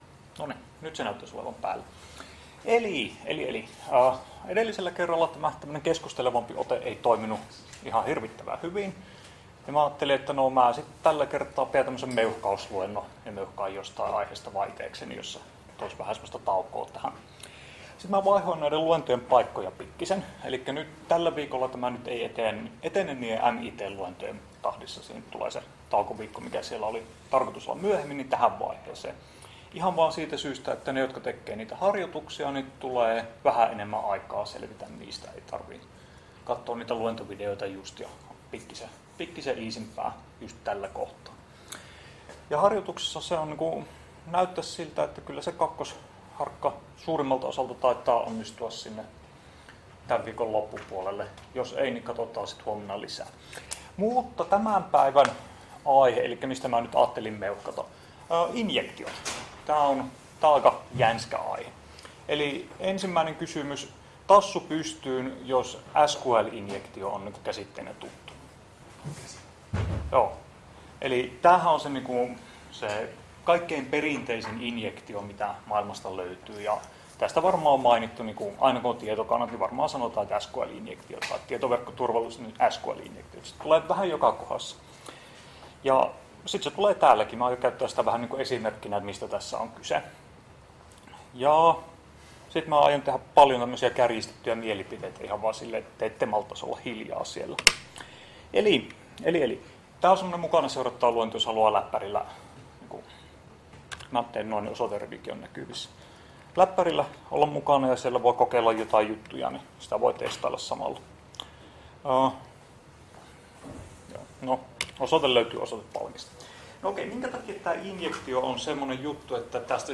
Luento 12.6. a755dc4cc2a242ff95698ad794f1b478